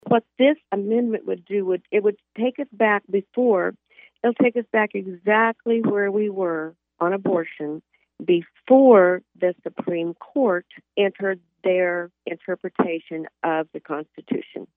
64th District Rep. Suzi Carlson of Clay Center says while she’s not in favor much of amending the Kansas Constitution, she does support the proposal in this case.